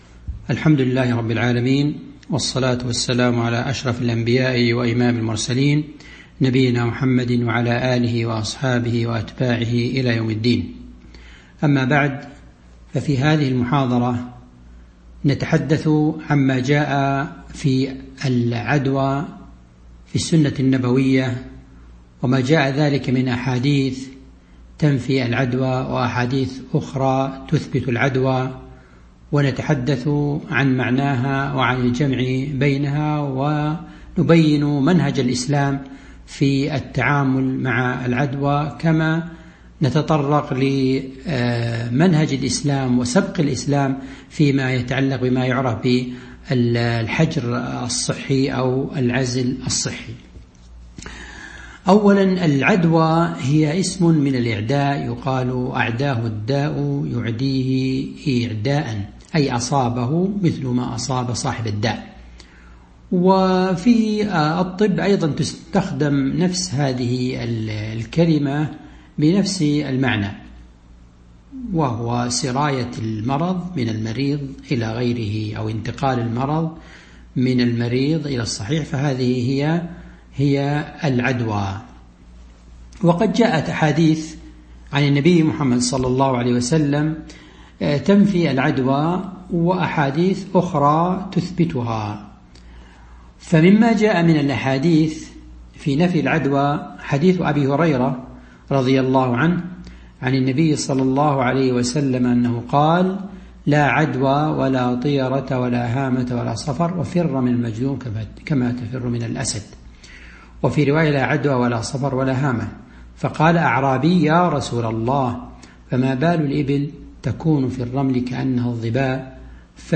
تاريخ النشر ١٦ ذو القعدة ١٤٤٢ هـ المكان: المسجد النبوي الشيخ